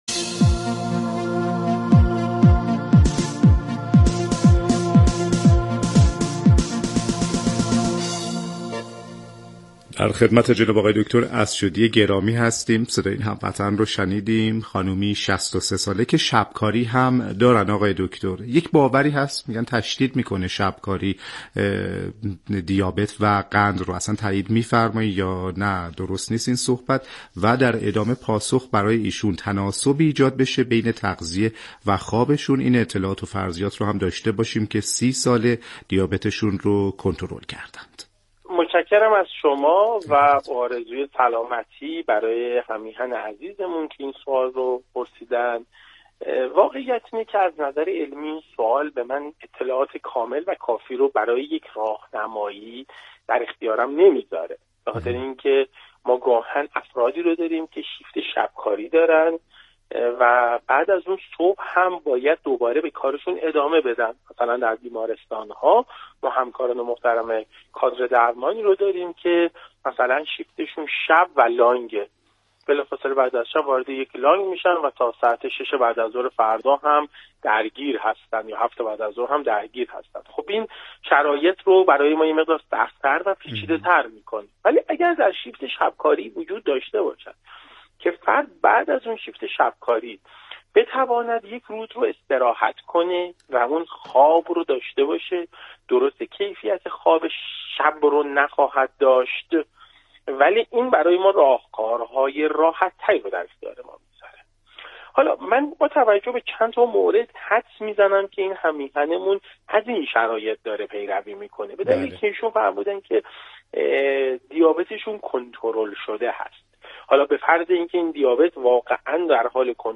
/صوت آموزشی/